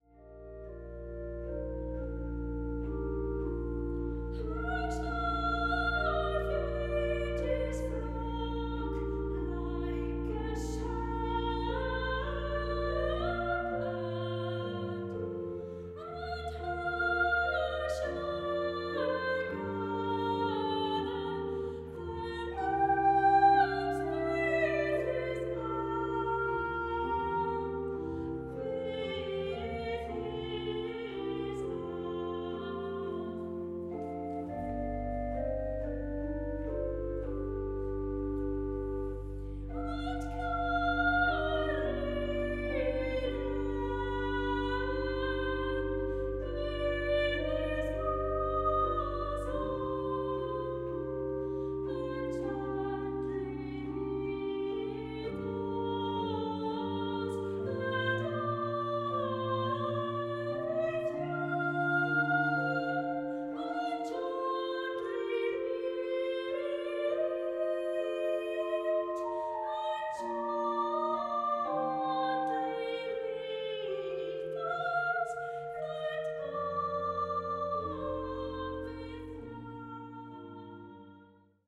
Klassische Kirchenkonzerte